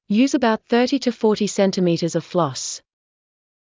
ﾕｰｽﾞ ｱﾊﾞｳﾄ ｻｰﾃｨ ﾄｩ ﾌｫｰﾃｨ ｾﾝﾃｨﾐｰﾀｰｽﾞ ｵﾌﾞ ﾌﾛｽ